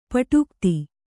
♪ paṭūkti